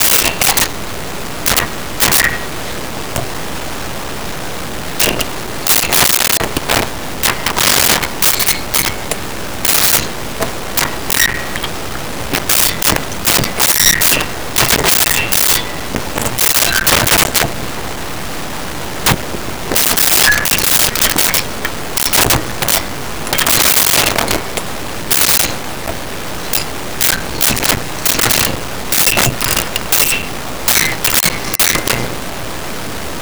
Glass Clinks And Motion 03
Glass Clinks And Motion 03.wav